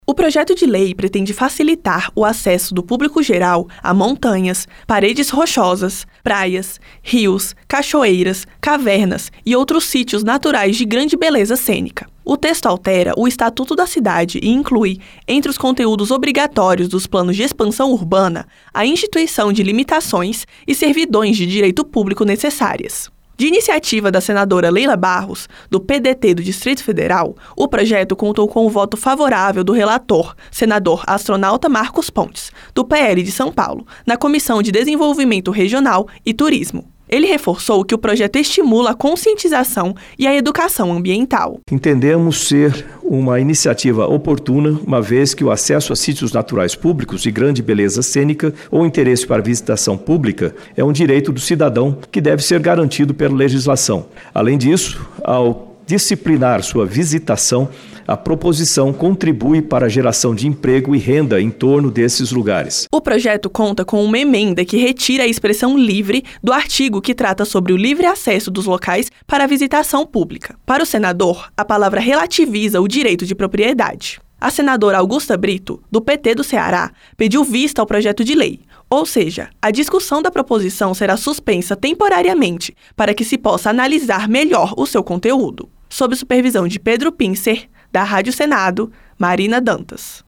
Rádio Senado
O texto altera o Estatuto da Cidade para assegurar o acesso público a montanhas, paredes rochosas, praias e outros sítios naturais. De iniciativa da senadora Leila Barros (PDT-DF), a proposta conta com parecer favorável do relator, senador Astronauta Marcos Pontes (PL-SP).